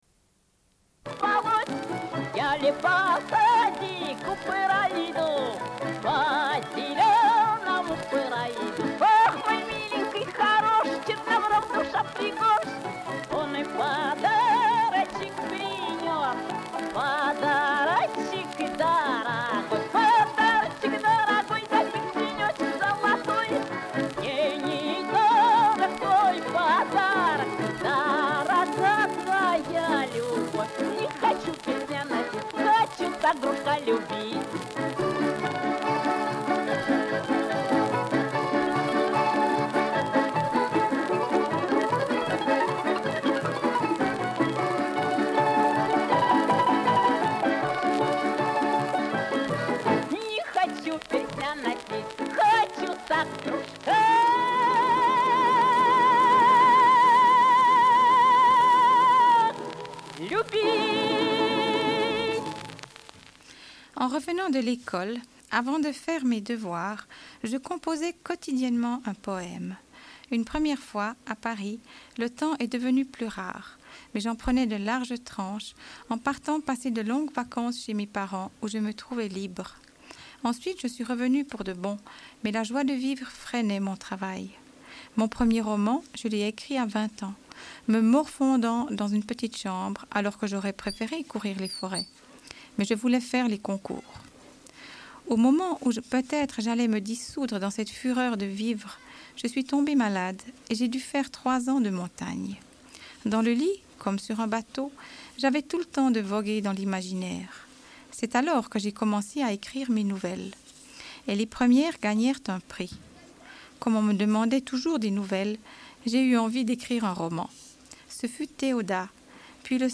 Une cassette audio, face B31:39